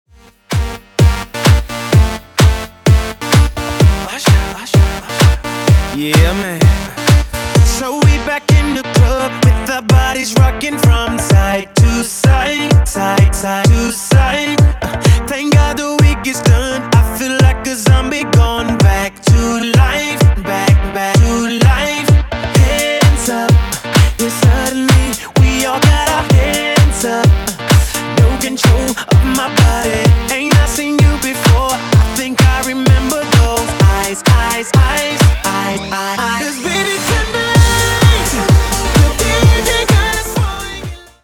Genres: 2000's , RE-DRUM
Clean BPM: 130 Time